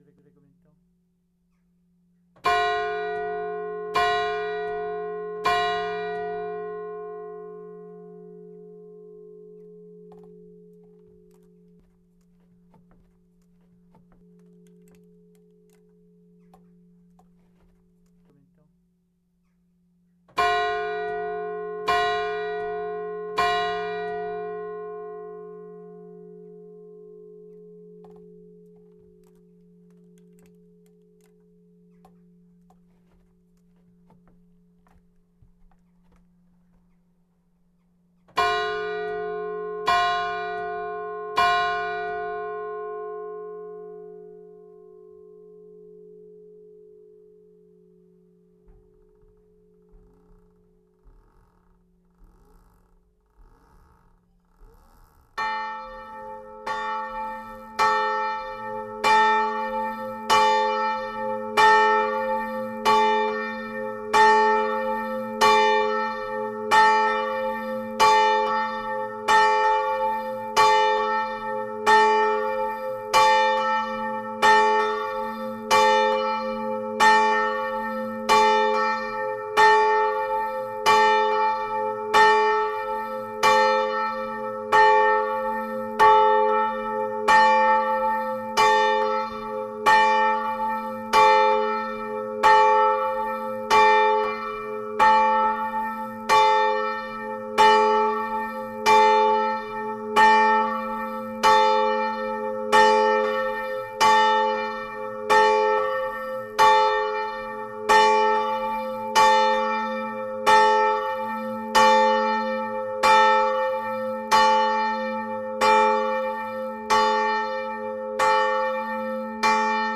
Le petit clocher bien sympathique possède deux cloches.
Le clocher est électrifié, un angelus classique sonne à midi. La volée est en montage rétrograde.
Vous pouvez écouter ces cloches ci-dessous, à l'angélus :
Hum : 176 Hz. Prime : 375 Hz. Tierce : 424 Hz. Nominal : 706 Hz. Superquint : 1060 Hz. Oct. Nom. 1465,5 Hz. Note : F#(1)+23. Nombre d'harmoniques détectées : 19.
Hum : 222,5 Hz. Prime : 424,5 Hz. Tierce : 523,5 Hz. Quint : 651 Hz. Nominal : 875 Hz. Superquint : 1314,5 Hz. Oct. Nom. 1813,5 Hz. Note : Ab(1)+37. Nombre d'harmoniques détectées : 24.
Cela fait donc une sonnerie en fa#, lab. C'est un peu bizarre, mais à deux cloches, ce n'est pas dissonant.
blanmont.mp3